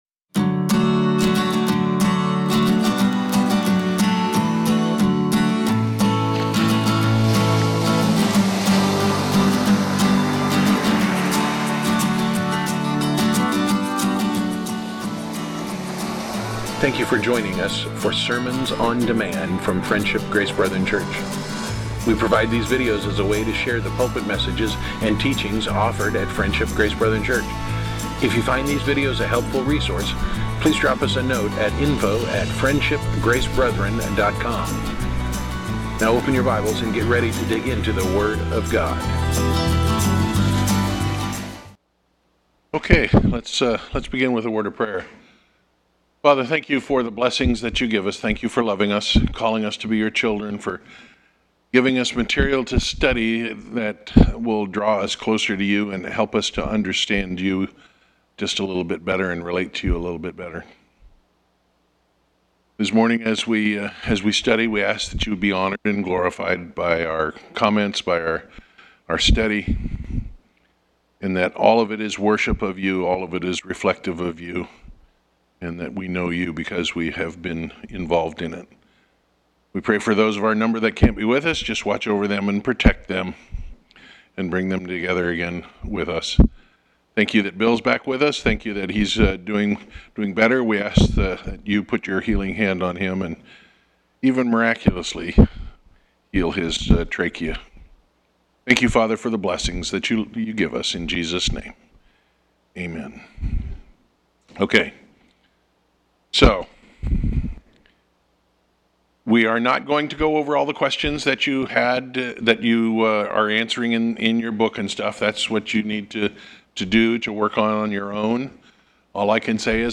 Series: Fundamentals of the Faith, Sunday School